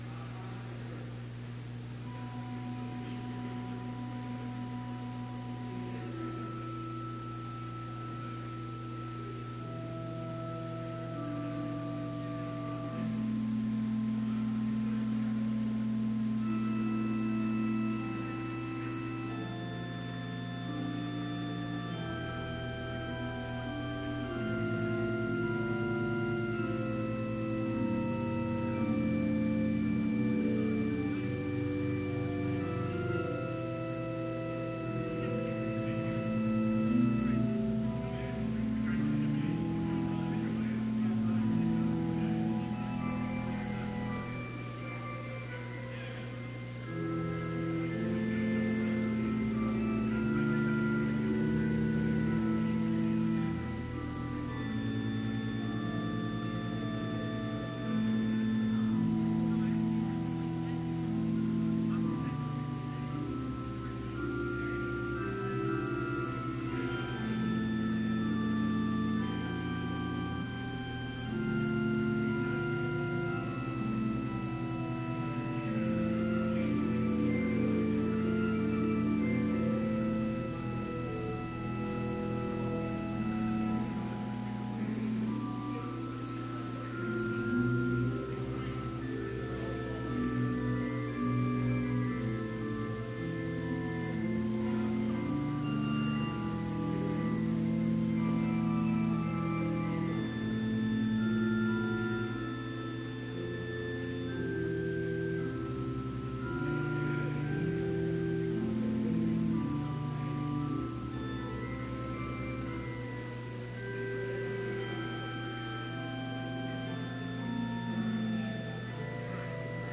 Complete service audio for Chapel - February 20, 2018
Watch Listen Complete Service Audio file: Complete Service Sermon Only Audio file: Sermon Only Order of Service Prelude Hymn 253, vv. 1-4, Rise My Soul to Watch and Pray Reading: Luke 22:31-32 Devotion Prayer Hymn 253, vv. 5 & 6, But while watching…